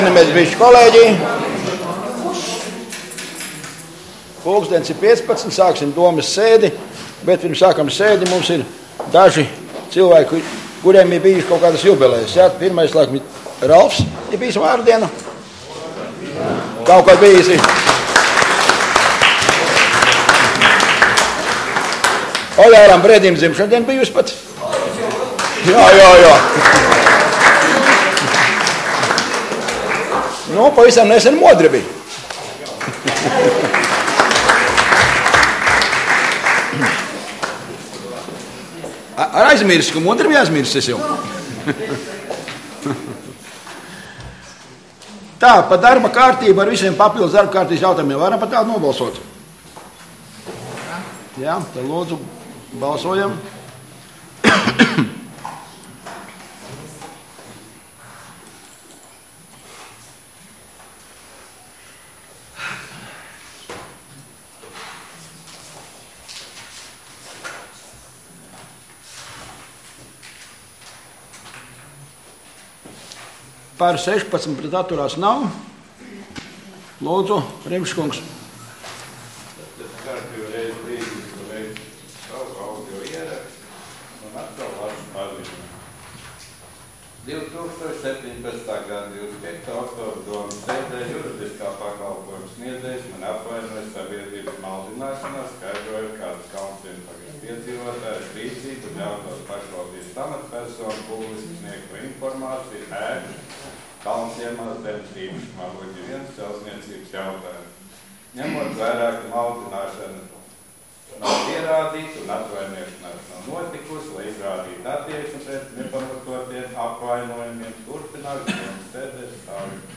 Domes sēde Nr. 13